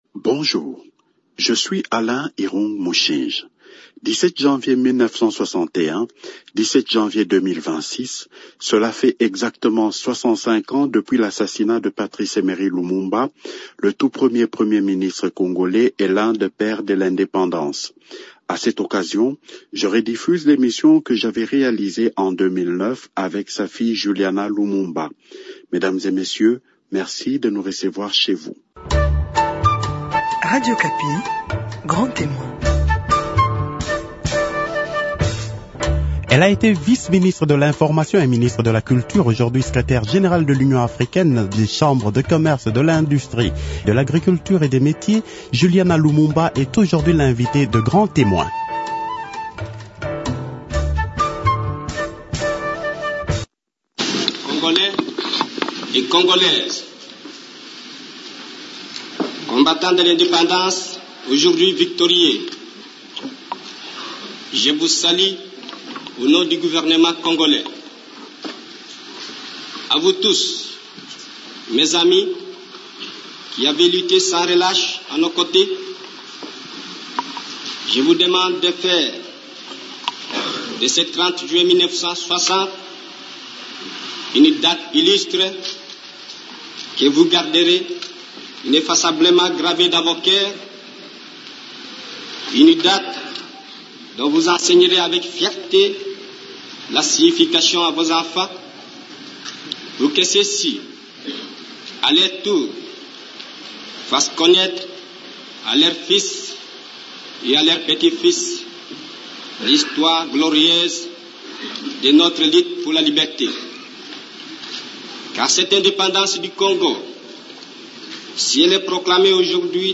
Juliana Lumumba parle du combat de son père
Comment les enfants Lumumba ont-ils réussi à quitter le pays alors qu’ils étaient en résidence surveillée ? Juliana Lumumba se confie à Radio Okapi.